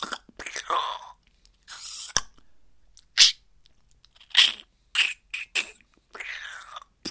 描述：Squelchy模式4小节135bpm
Tag: 创意 敢-19 循环 口技